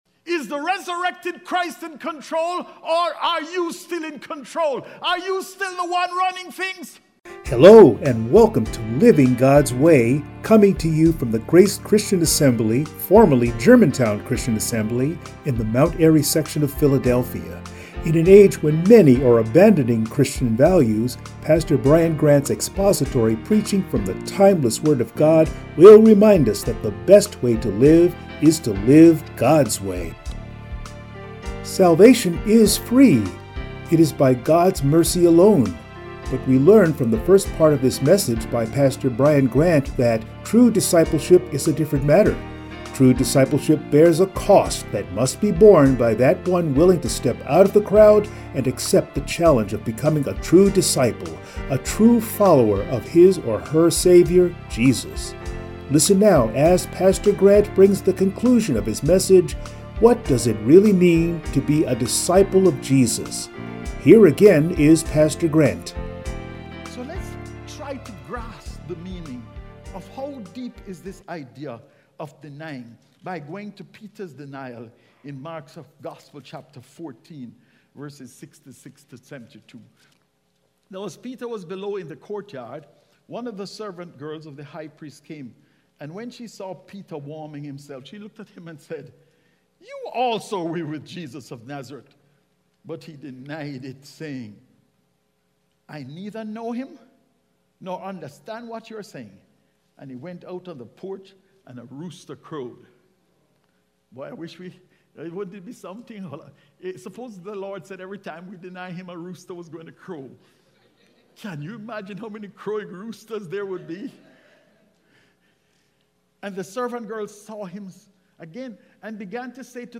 Passage: Mark 8:34-38 Service Type: Sunday Morning